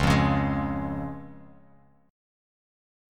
C# Chord
Listen to C# strummed